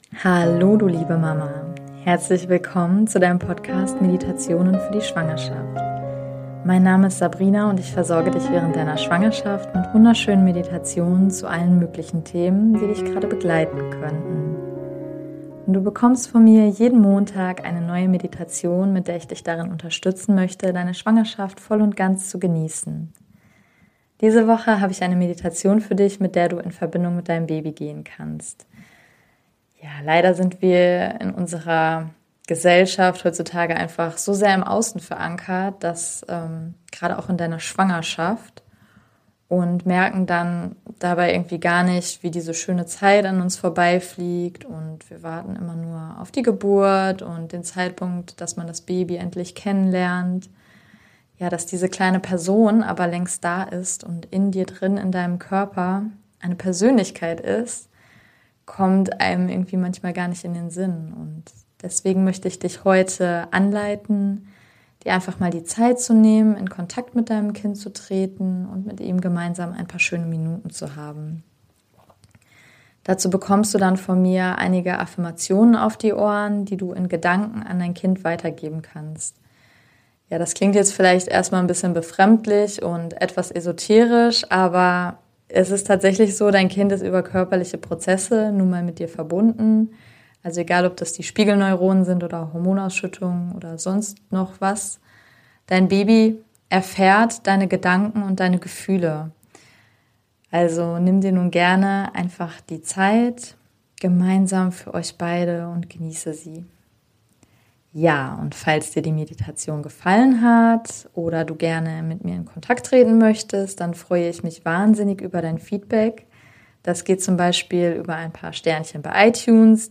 #012 - Meditation Verbinde dich mit deinem Baby - Liebende Affirmationen ~ Meditationen für die Schwangerschaft und Geburt - mama.namaste Podcast